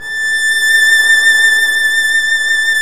Index of /90_sSampleCDs/Roland L-CD702/VOL-1/STR_Violin 1 vb/STR_Vln1 % + dyn